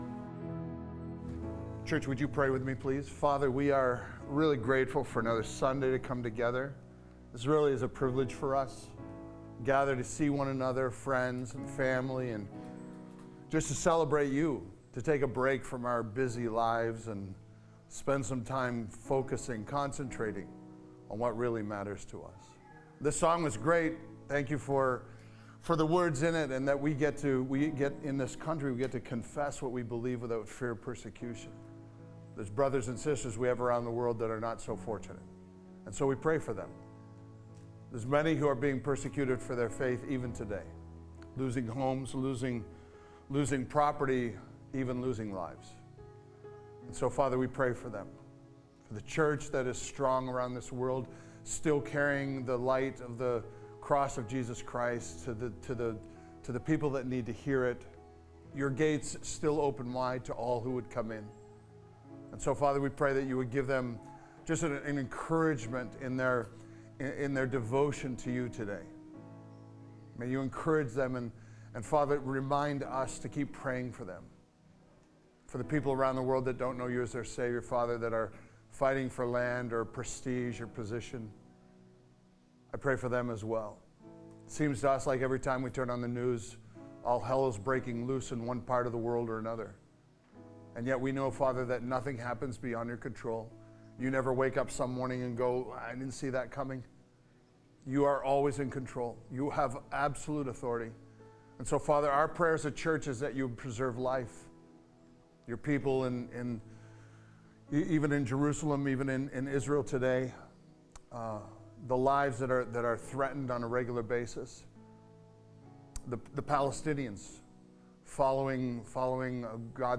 Village Church East: Sermons